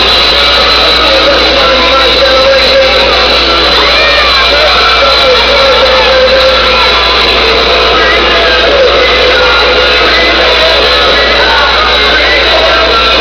(This one isn't too clear because of all the screaming)